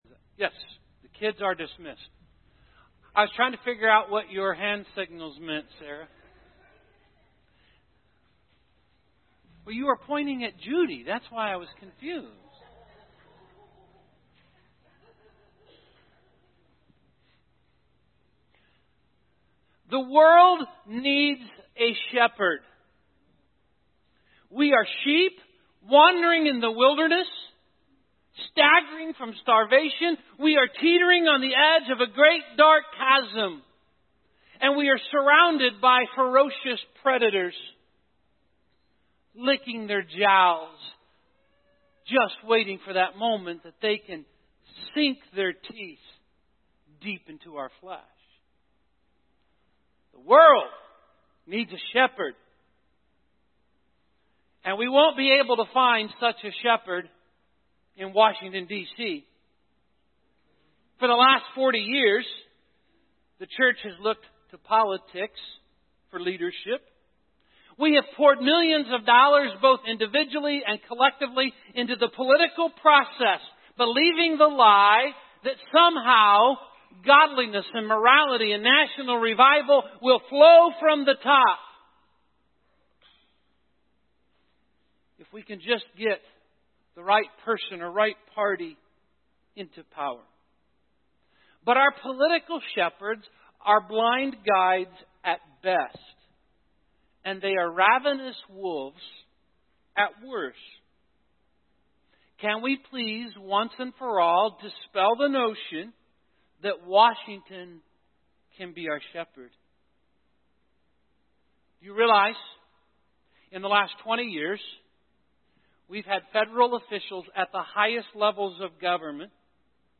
Psalms Core 52 Audio Sermon Save Audio Save PDF In Psalm 23